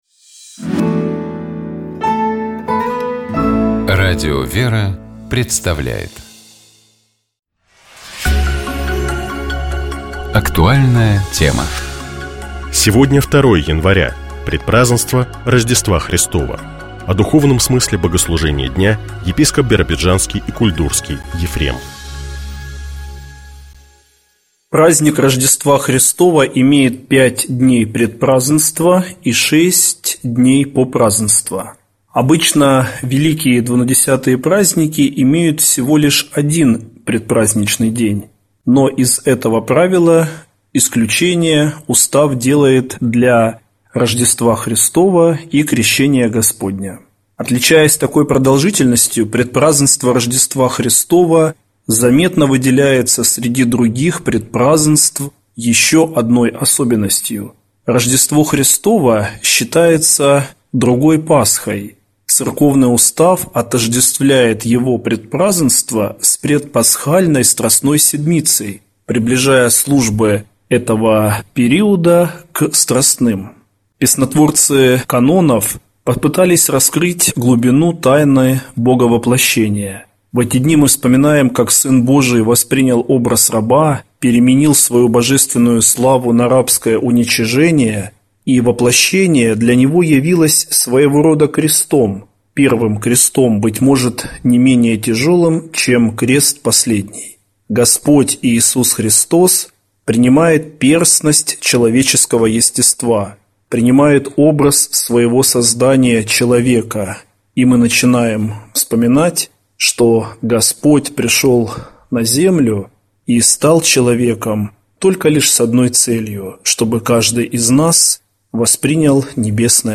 О духовном смысле богослужения дня, — епископ Биробиджанский и Кульдурский Ефрем.